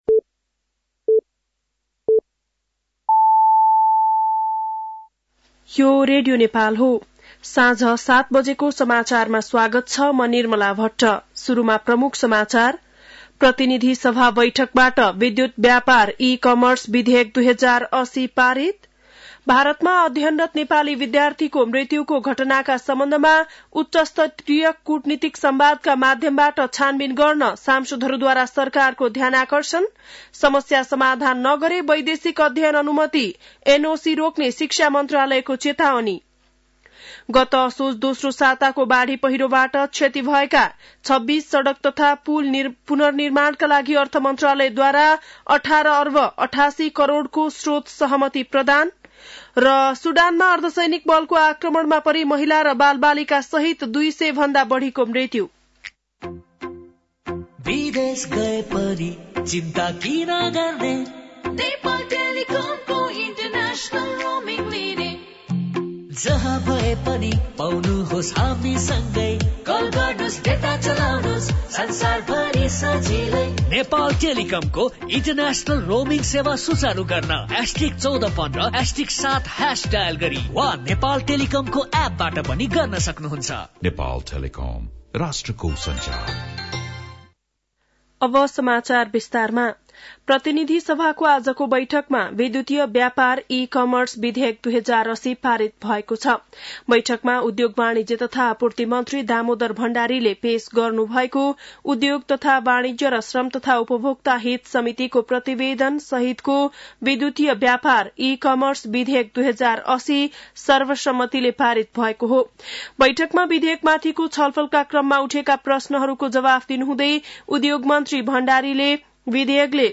बेलुकी ७ बजेको नेपाली समाचार : ७ फागुन , २०८१